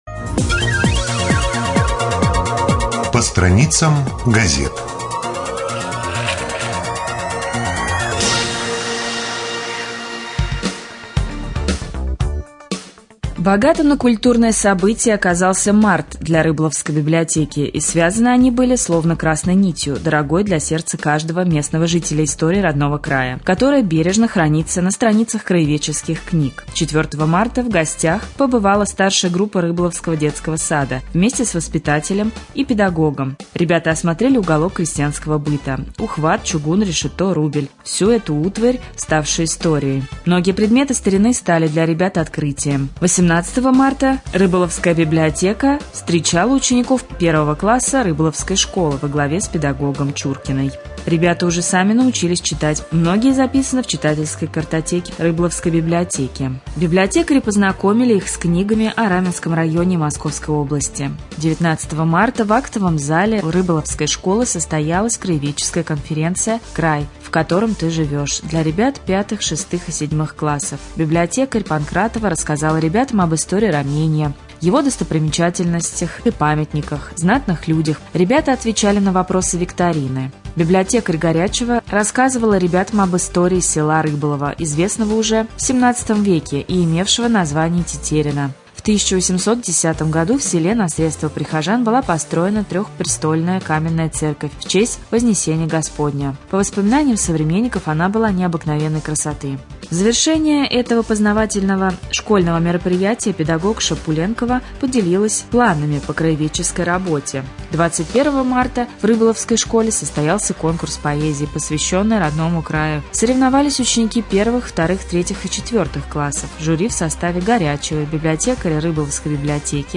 03.04.2014г. в эфире раменского радио - РамМедиа - Раменский муниципальный округ - Раменское